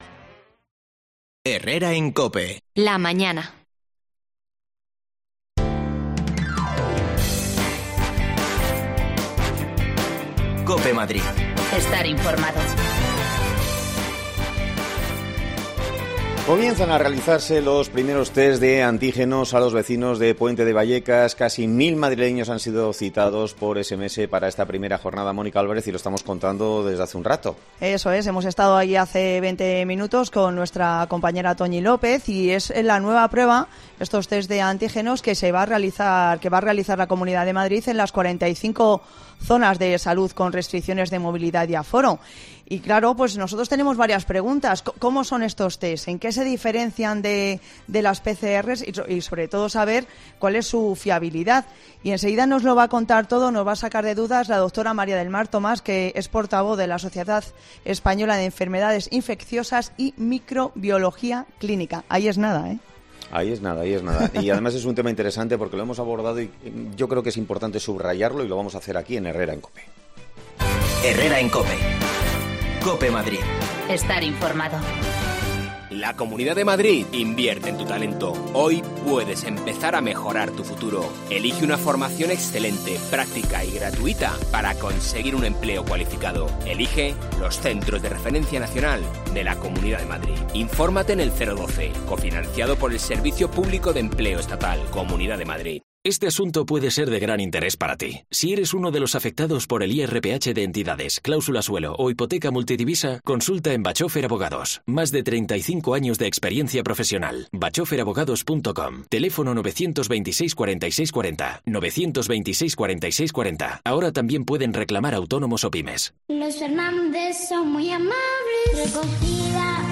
Escucha ya las desconexiones locales de Madrid de Herrera en COPE en Madrid y Mediodía COPE en Madrid .
Las desconexiones locales de Madrid son espacios de 10 minutos de duración que se emiten en COPE , de lunes a viernes.